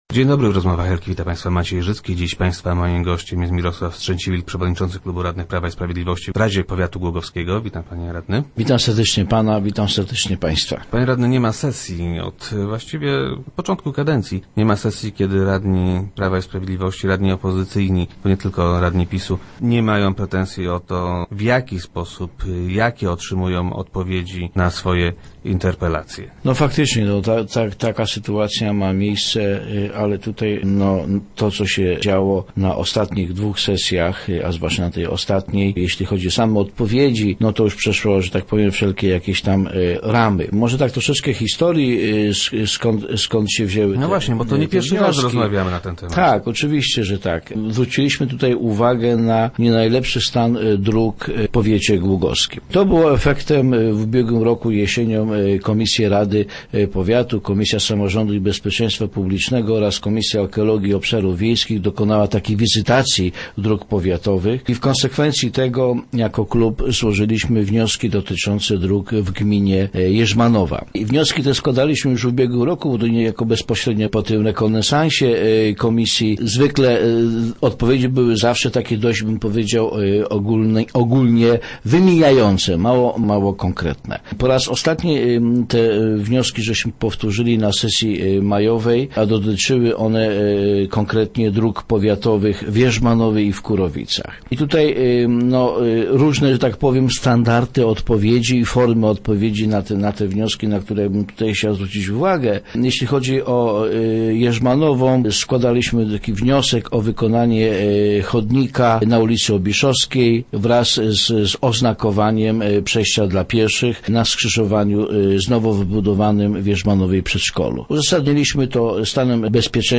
0107_strzeciwilk_do_rozmw.jpgWraca temat odpowiedzi na interpelacji opozycyjnych radnych rady powiatu głogowskiego. - Nie dość, że otrzymujemy je późno, to często jeszcze są bardzo lakoniczne, a czasem kuriozalne – twierdzi Mirosław Strzęciwilk, przewodniczący klubu radnych PiS, który był gościem Rozmów Elki.